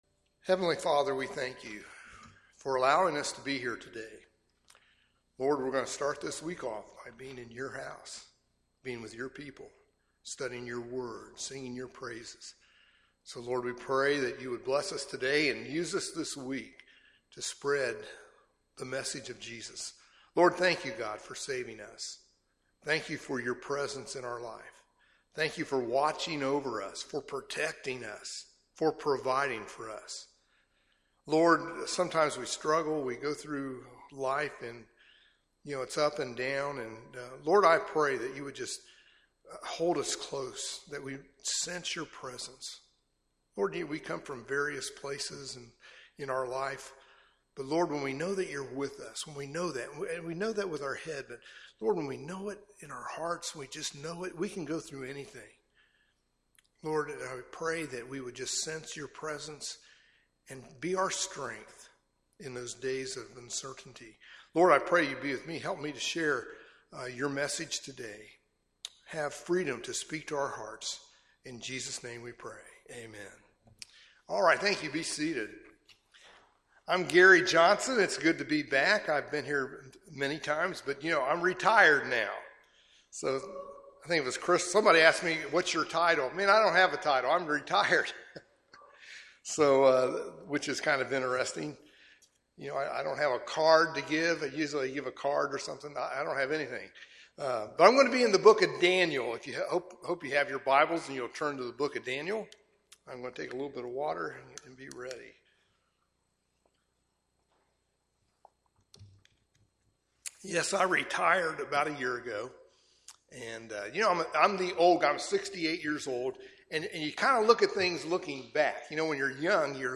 過去的佈道 - 美亞美華人浸信教會